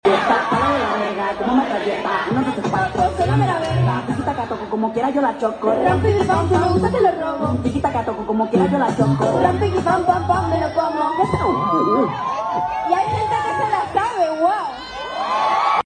en su Pop Up en CDMX